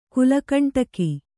♪ kulakakaṇṭaki